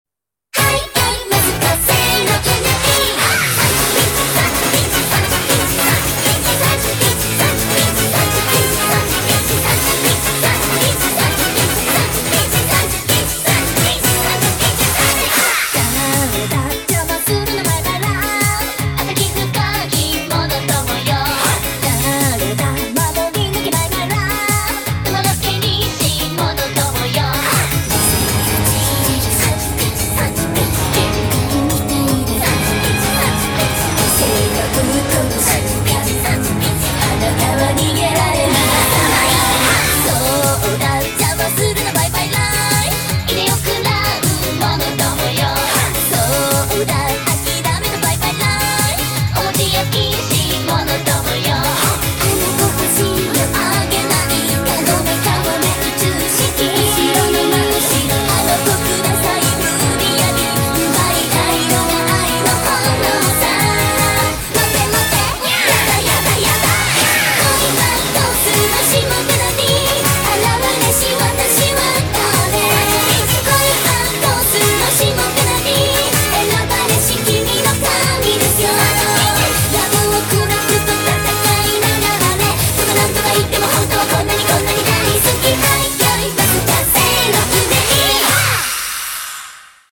BPM158
Audio QualityLine Out